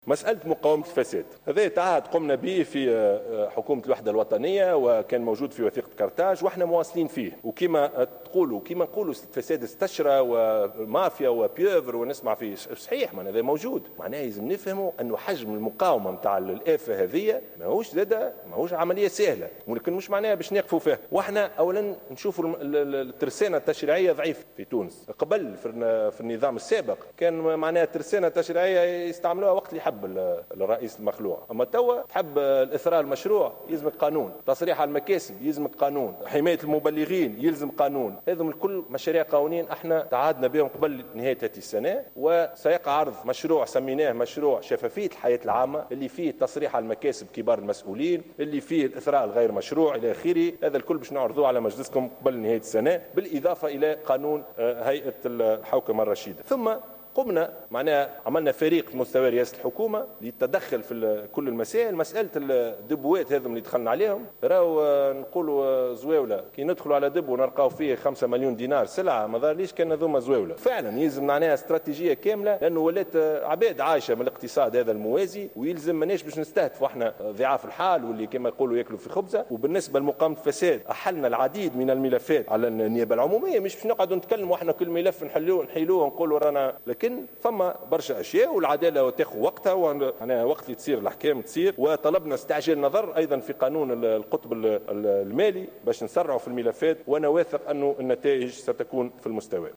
وأكد يوسف الشاهد، في رده على استفسارات النواب خلال الجلسة العامة المخصصة للنقاش العام حول مشروعي ميزانية الدولة وقانون المالية لسنة 2016، على تعهد الحكومة بمحاربة الفساد ومواصلة هذا المنحى الذي تبنته منذ بداية عملها، مقرا باستشراء الفساد والتهريب في البلاد، حيث لفت إلى صعوبة مقاومة هذه الآفة لـ "ضعف الترسانة التشريعية"، على حد قوله.